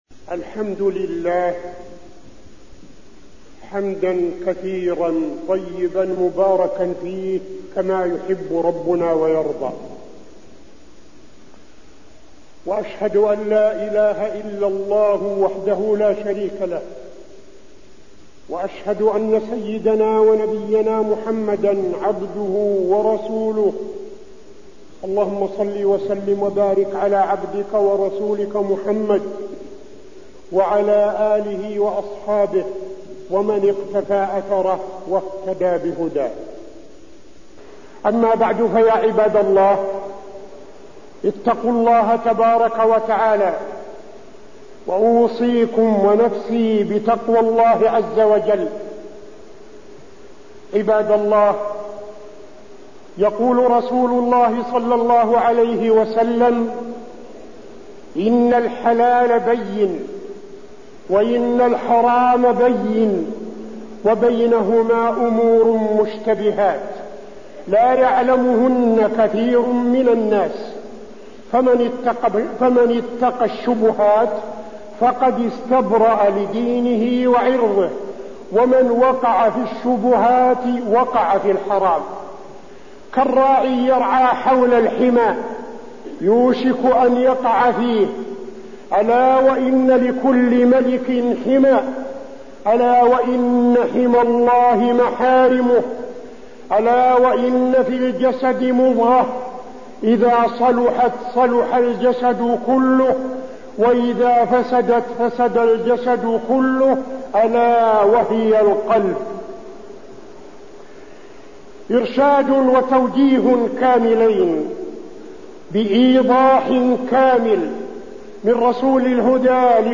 تاريخ النشر ٢٩ رجب ١٤٠٥ هـ المكان: المسجد النبوي الشيخ: فضيلة الشيخ عبدالعزيز بن صالح فضيلة الشيخ عبدالعزيز بن صالح الحلال بين والحرم بين